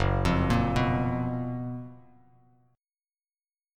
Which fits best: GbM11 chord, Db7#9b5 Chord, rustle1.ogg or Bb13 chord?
GbM11 chord